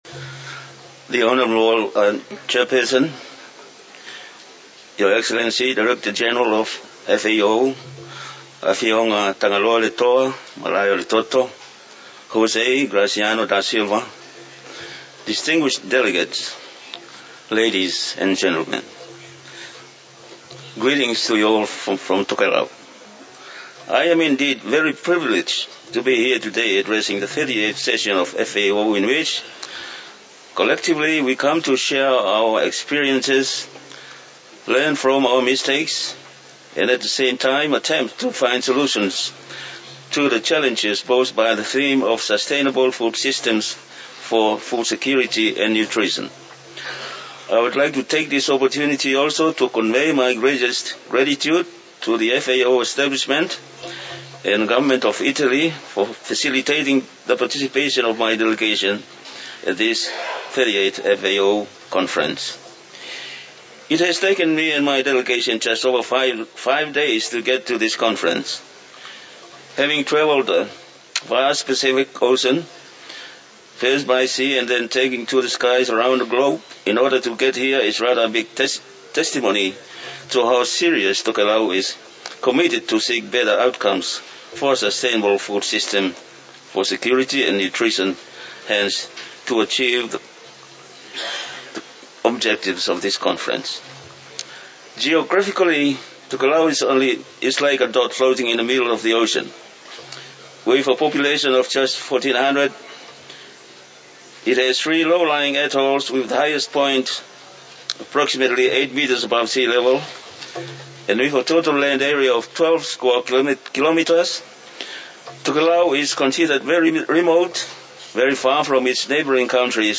FAO Conference
Statements by Heads of Delegations under Item 9:
The Honourable Kelihiano Kalolo Minister for Economic Development, Natural Resources and Environment of Tokelau (Associate member)